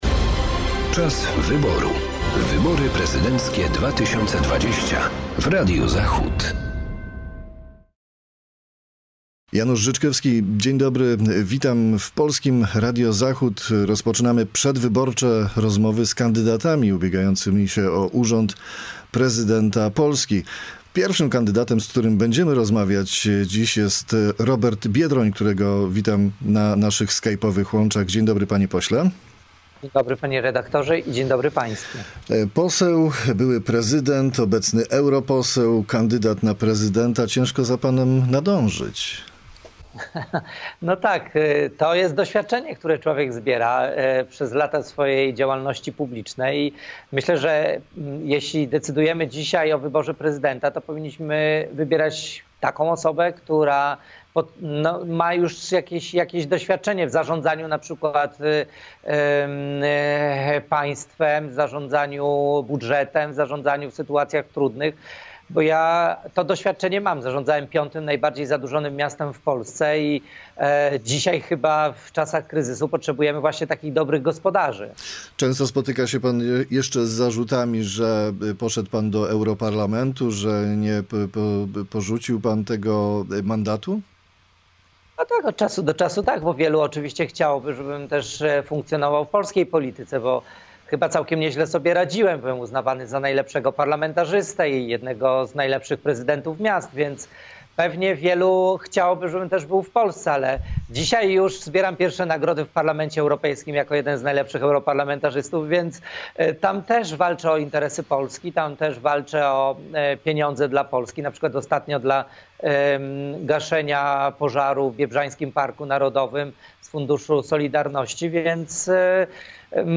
Z kandydatem SLD na urząd Prezydenta RP rozmawia